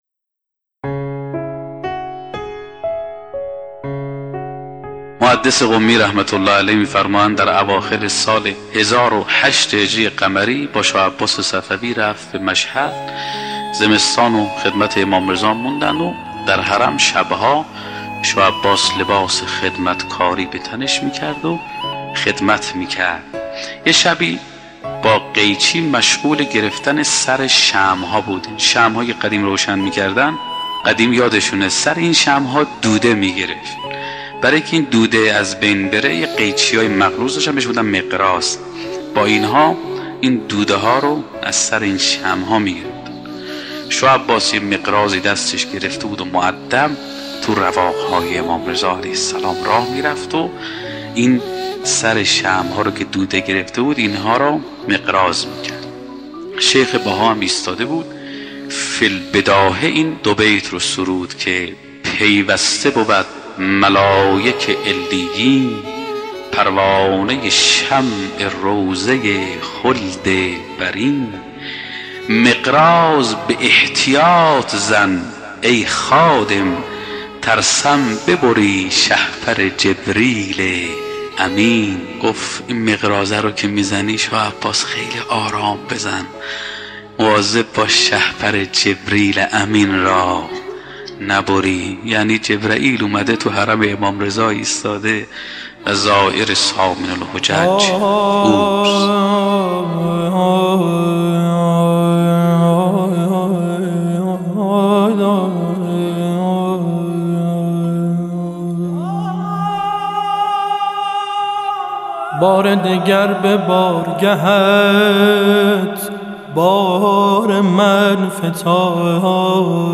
در یکی از سخنرانی های خود به ماجرای زیارت محدث قمی و شاه عباس صفوی از حرم امام رضا (ع) اشاره می کند: